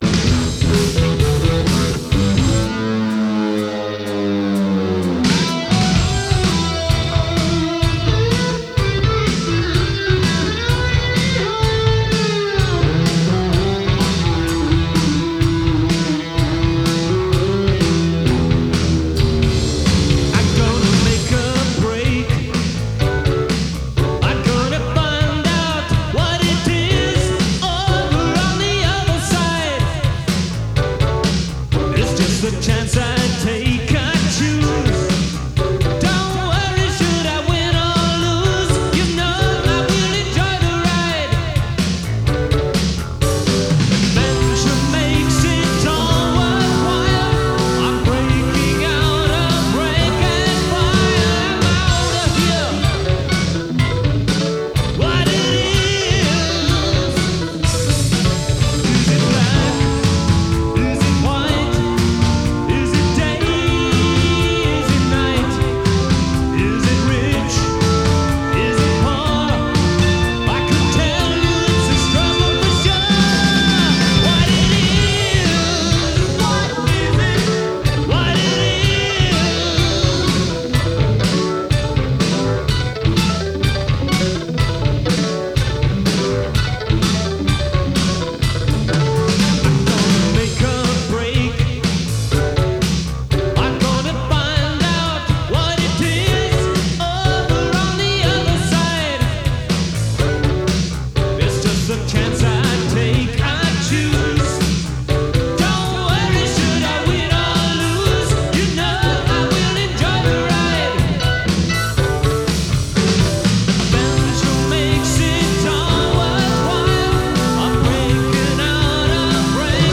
They were a small band with a big sound (round and loud)
on keyboards and vocals
bass guitar and vocals
drums and vocals